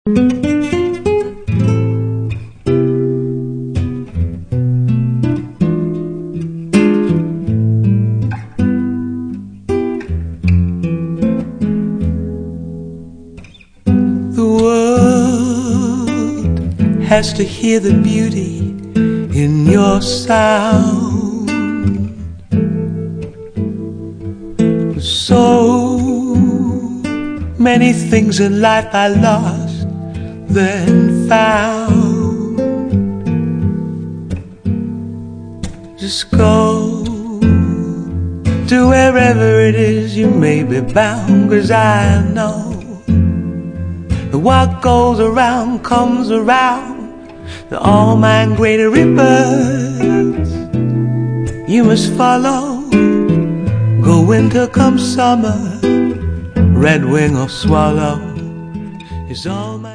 voce
chitarre